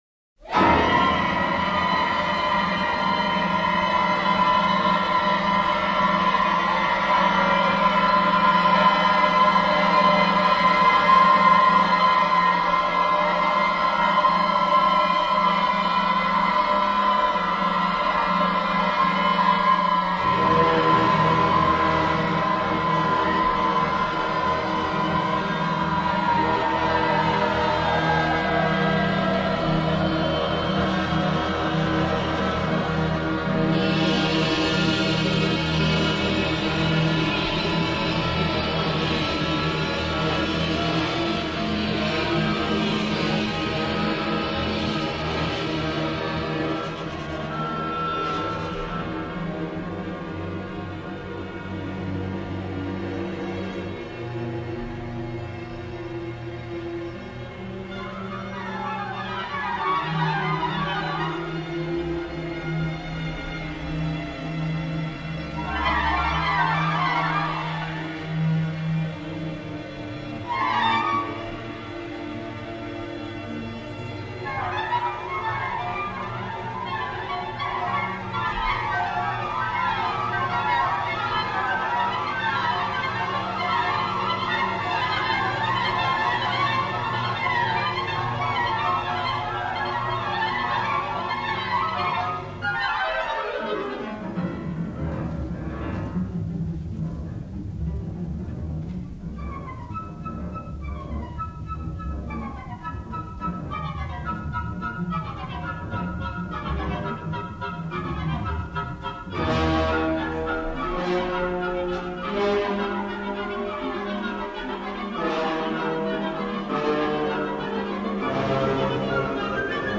Piano obligato